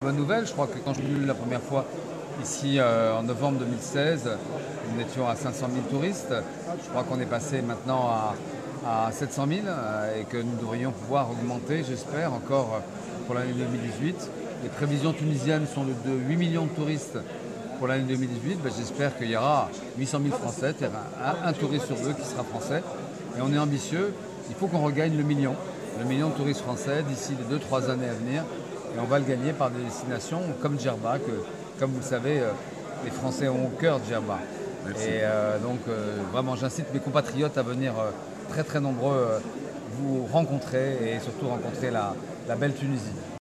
سفير فرنسا بتونس